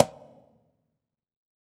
PBONGO SLP.wav